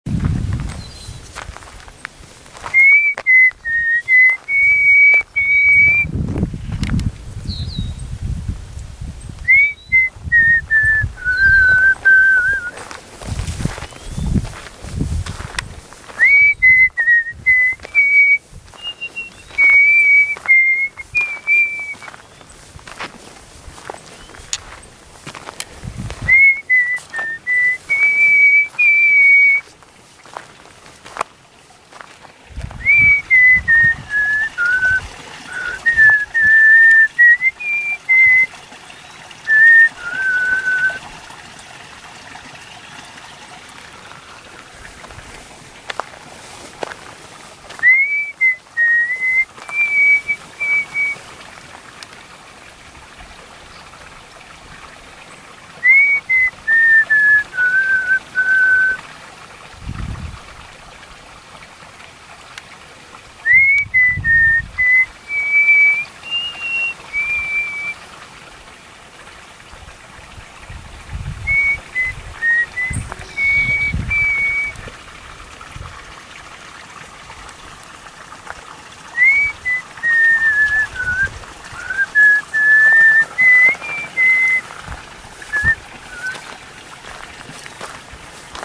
[I am whistling the ‘Lion Song.”]
Location: Backbone Trail South of Encinal Canyon Road, Santa Monica Mountains, CA